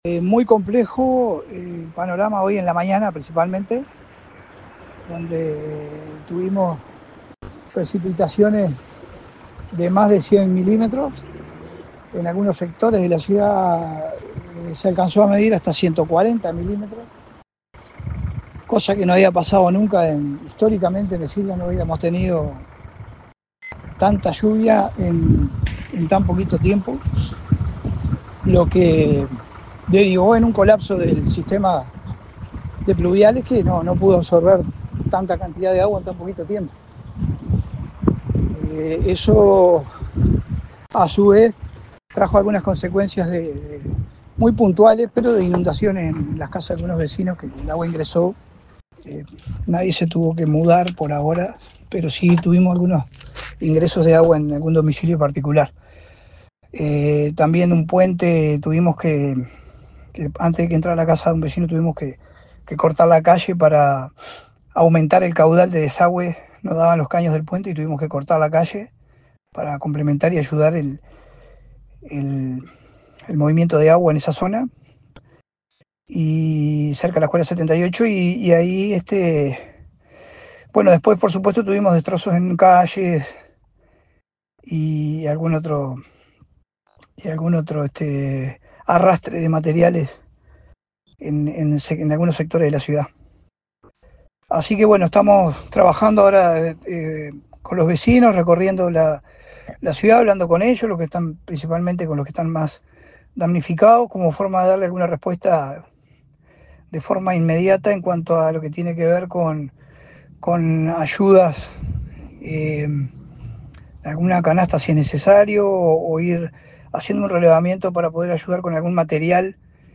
Las mismas superaron los 100 mm en tan solo 40 minutos, incluso el algunas zonzas se llegó a los 140 mm, contó el alcalde Leonardo Giménez.
LEONARDO-GIMENEZ-INUNDACIONES-EN-EDILDA.mp3